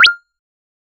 Final 'DING' - kristal berraklığında çan sesi. 0:05 cding ding sound of coins 0:02 A short, high-pitched synthetic 'blip' or 'ding' with a slight upward tone, indicating success or notification 0:01
a-short-high-pitched-synt-l2ktjtna.wav